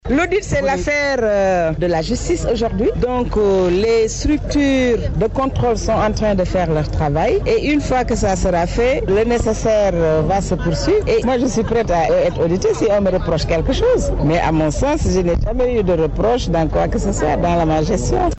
La Secrétaire générale de la Présidence, Aminata Tall ne se reproche rien, c’est dans cette logique qu’elle se dit prête à être auditée par les pandores de la section d’enquête sur l’enrichissement illicite. C’était à l’occasion de l’Assemblée générale de son mouvement.